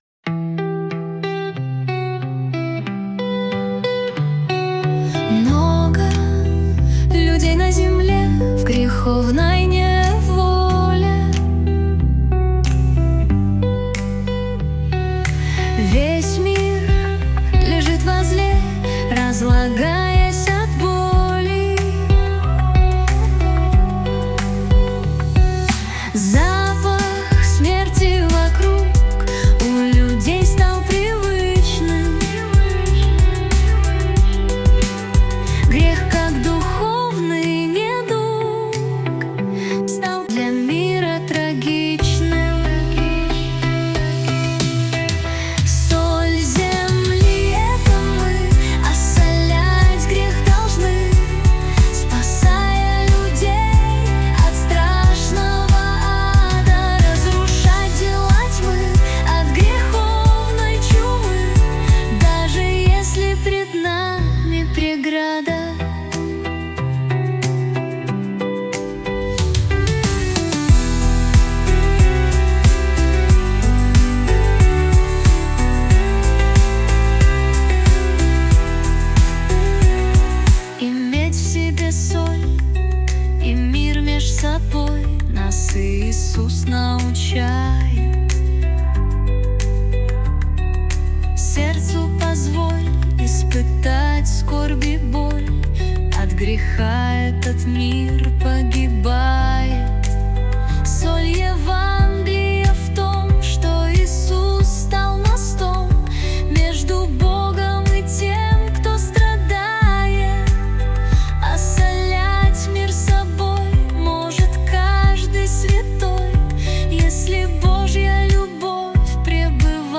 песня ai
260 просмотров 1104 прослушивания 73 скачивания BPM: 92